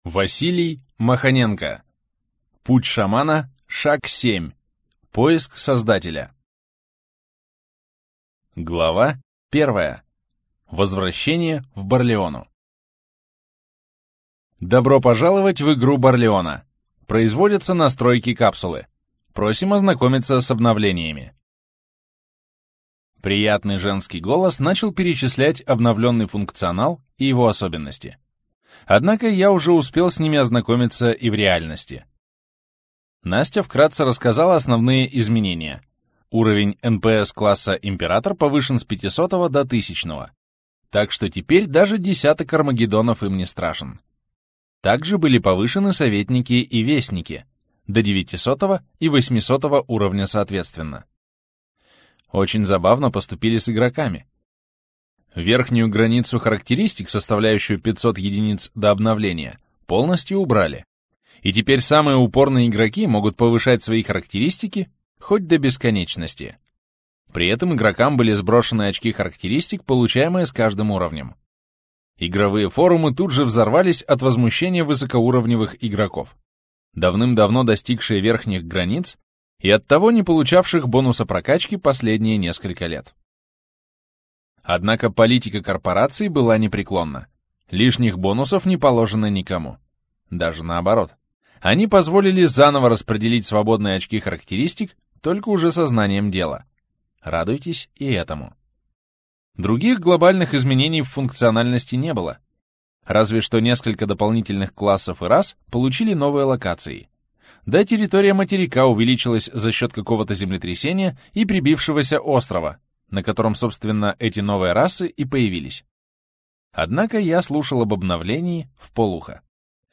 Аудиокнига Путь Шамана. Поиск Создателя | Библиотека аудиокниг